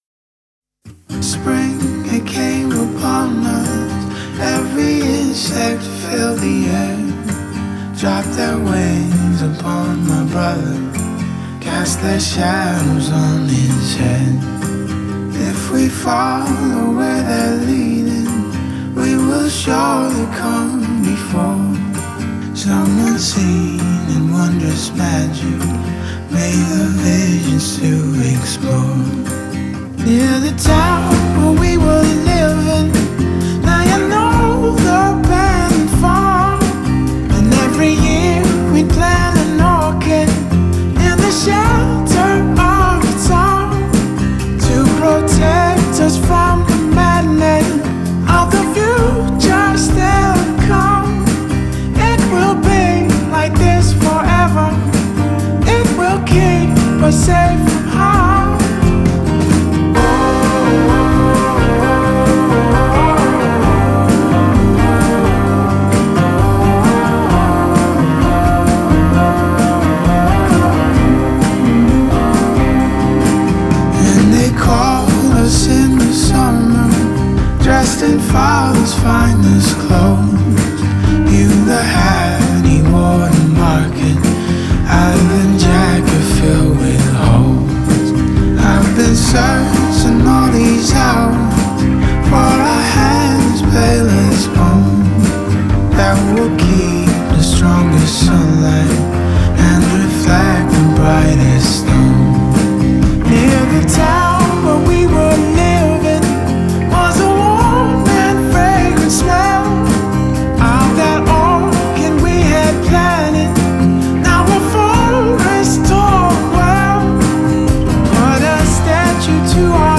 Irish singer-songwriter
hushed intimacy
a more organic arrangement
the organic tenacity of an R&B soul man
boasts a great melody